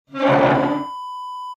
Chair scraping floor sound effect .wav #2
Description: The sound of a chair scraping on the floor
A beep sound is embedded in the audio preview file but it is not present in the high resolution downloadable wav file.
Keywords: chair, scrape, scraping, screech, screeching, move, moving, push, pushing, pull, pulling, drag, dragging, hardwood, wooden, floor
chair-scraping-floor-preview-2.mp3